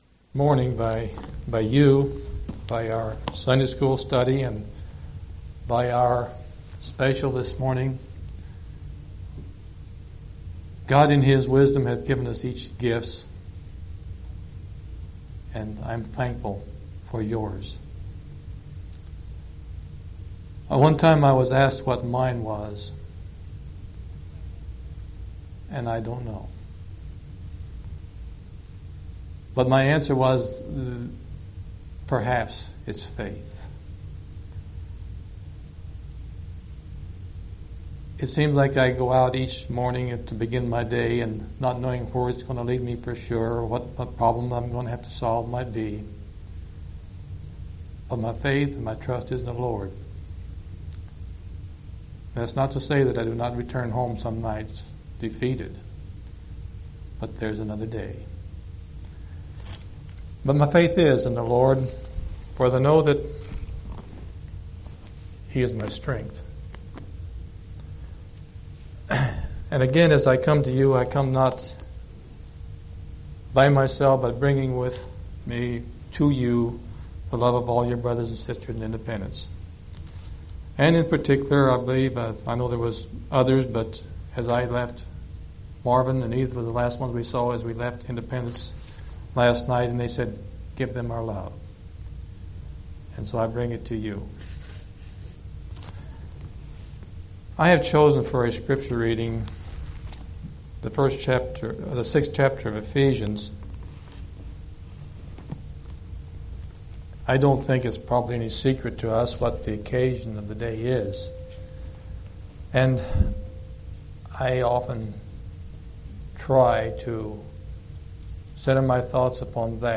6/19/1988 Location: Collins Local Event